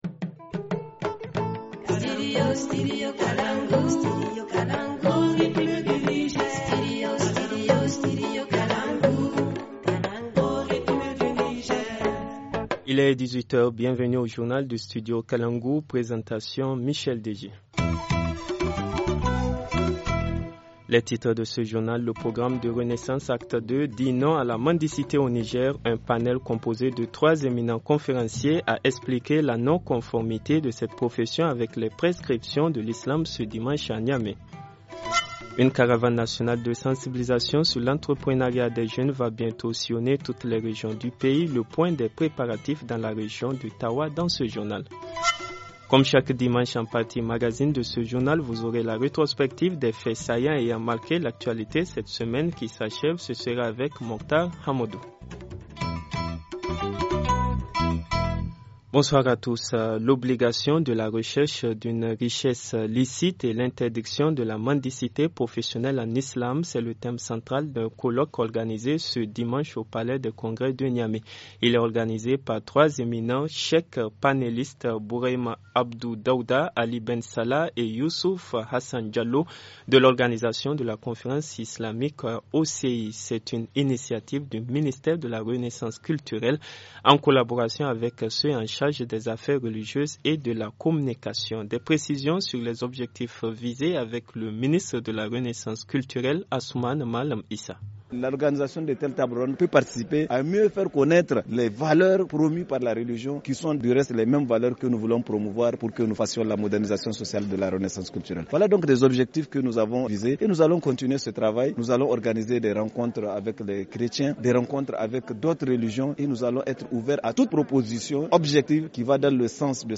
Journal du 10 juin 2018 - Studio Kalangou - Au rythme du Niger